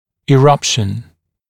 [ɪ’rʌpʃn] [и’рапшн] прорезывание (зуба)